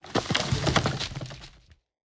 tree-leaves-1.ogg